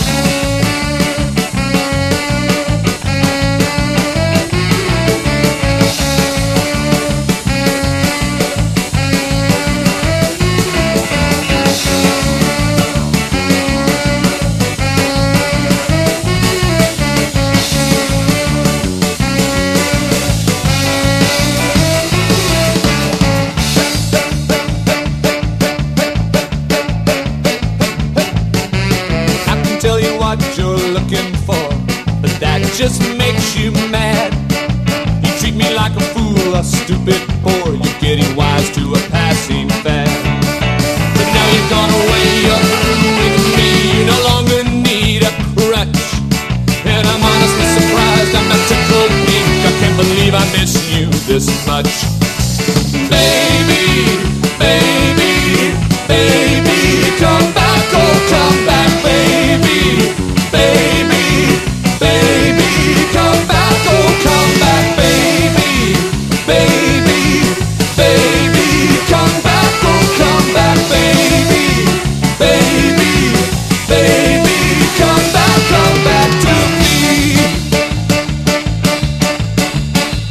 ROCK / 80'S/NEW WAVE. / NEW WAVE / POLKA / WORLD